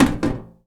metal_tin_impacts_movement_rattle_04.wav